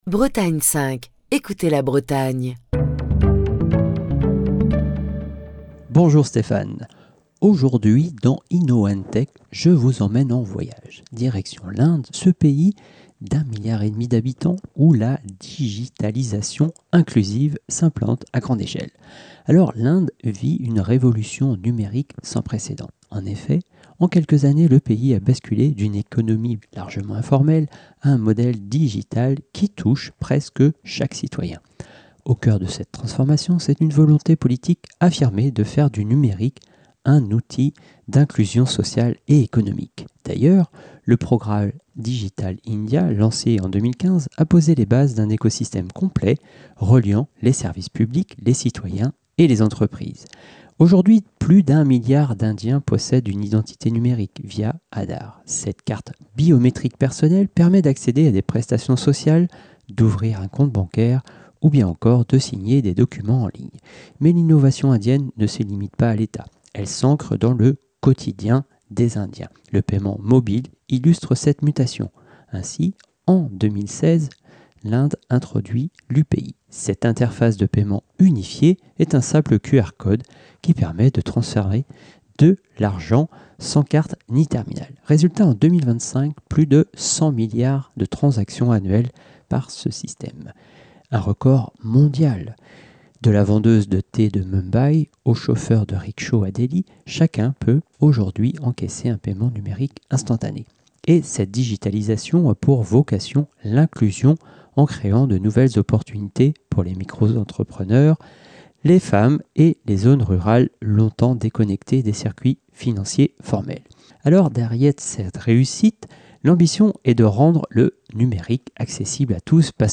Chronique du 17 novembre 2025.